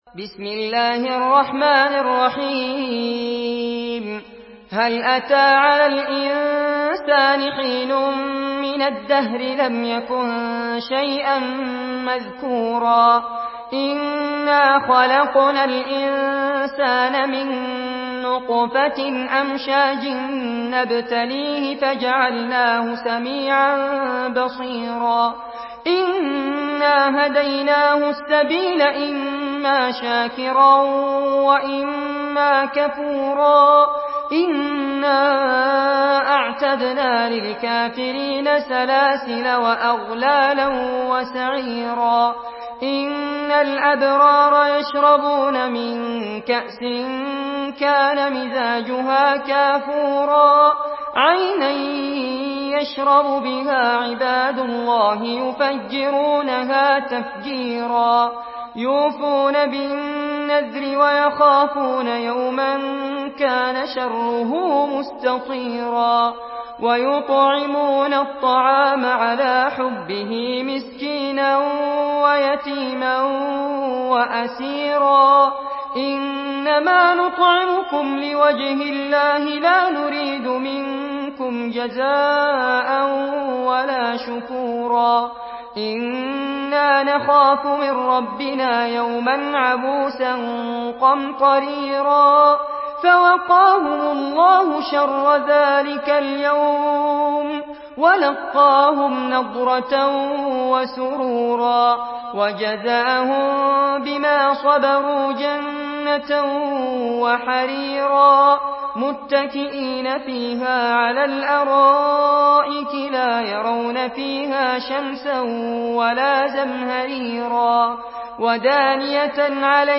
Surah الإنسان MP3 in the Voice of فارس عباد in حفص Narration
Surah الإنسان MP3 by فارس عباد in حفص عن عاصم narration.